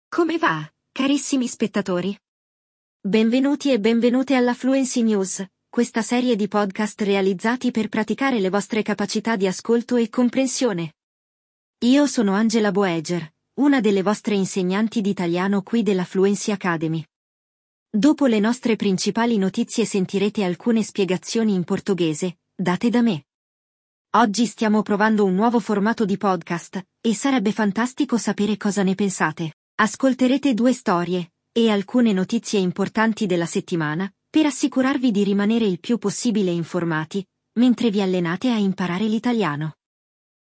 Conheça seu mais novo podcast de notícias, em italiano, claro!